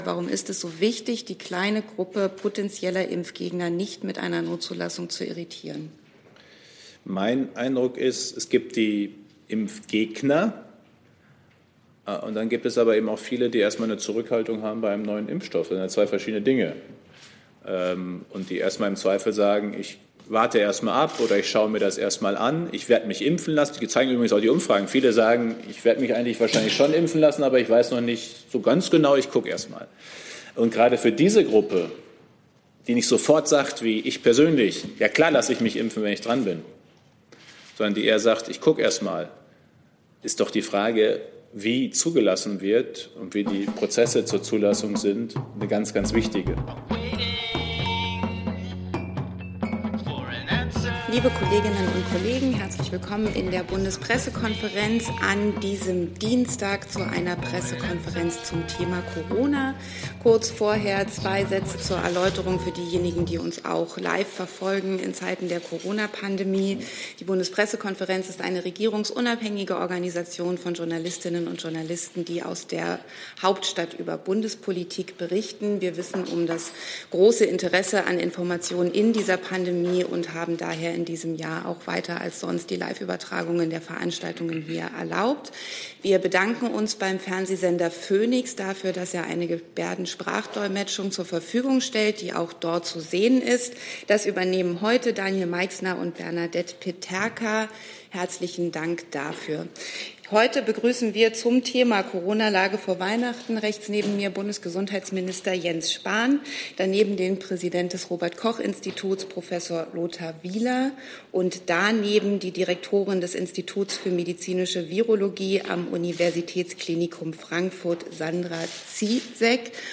Bundespressekonferenz